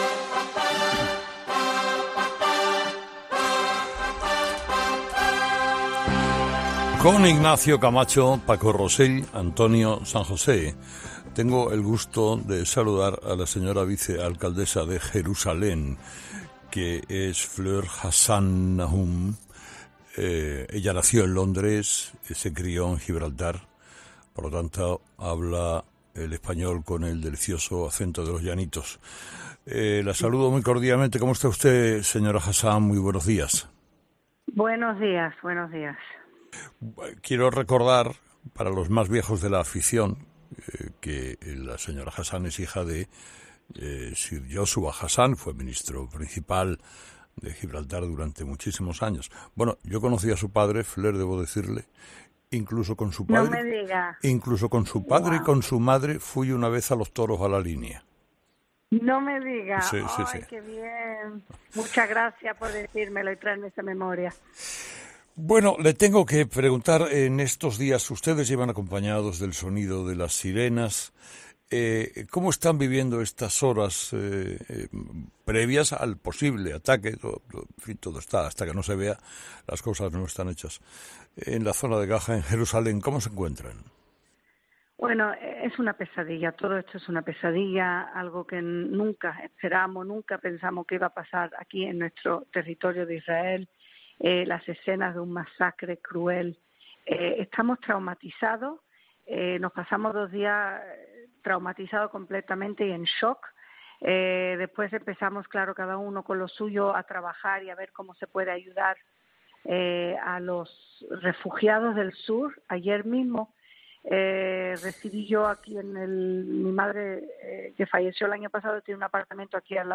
Carlos Herrera ha entrevistado este lunes a la vicealcaldesa de Jerusalén, Fleur Hassan-Nahoum , minutos después de que Egipto haya anunciado la reapertura del paso fronterizo de Rafah.